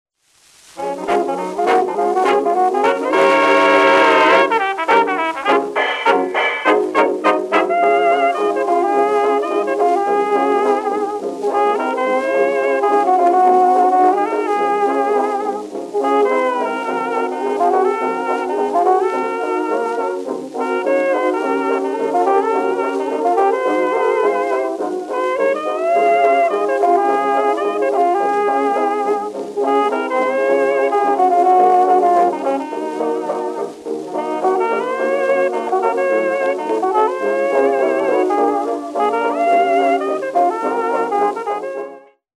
Fox trot
10kHz Low Pass (18dB/Octave)